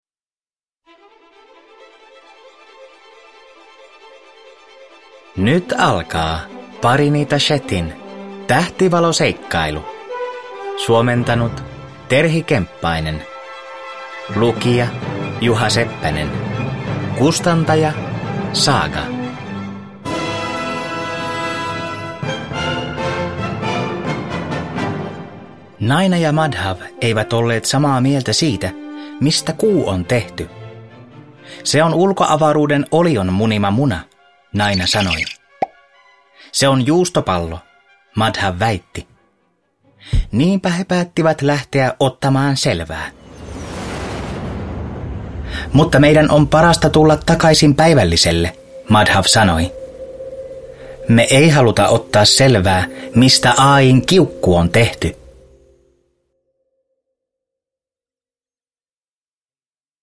Tähtivaloseikkailu – Elävöitetty äänikirja (ljudbok) av Upamanyu Bhattacharyya